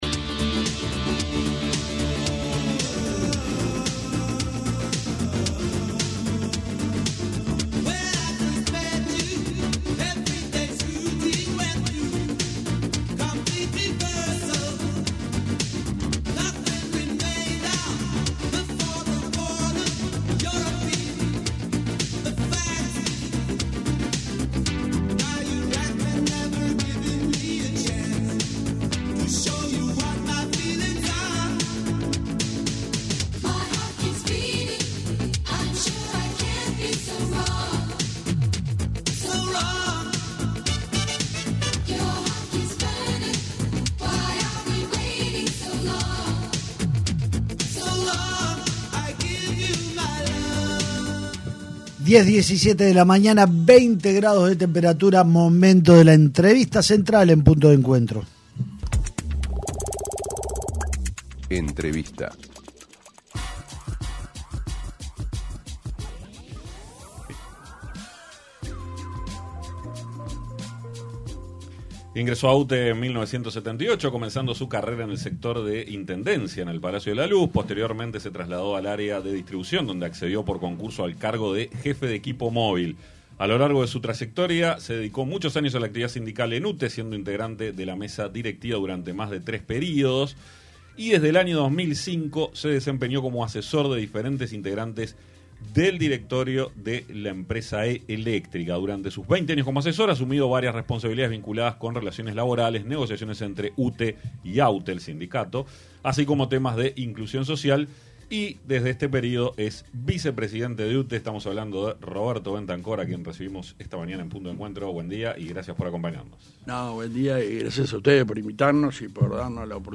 ENTREVISTA: ROBERTO BENTANCOR